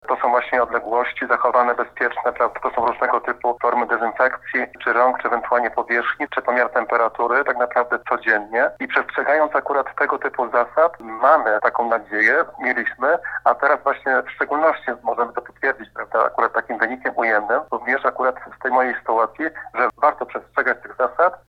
W rozmowie telefonicznej z Radiem Leliwa burmistrz podkreśla, że czeka teraz na oficjalne pismo ze sanepidu o zwolnieniu z kwarantanny i możliwości powrotu do pracy.